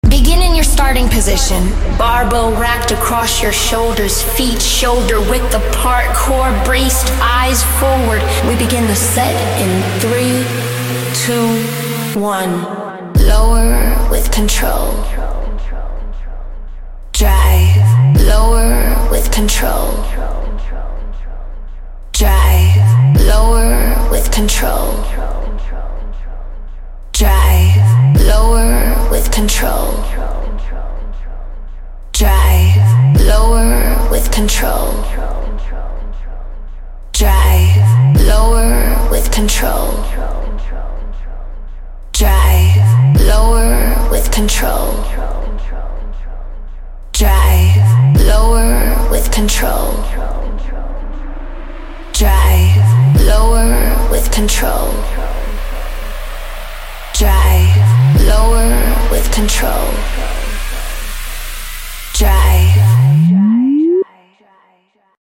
Using guided audio helps lock you into perfect tempo so you don’t rush reps or rely on momentum. Instead of counting in your head, you just follow the cues — making every rep consistent, safe, and effective.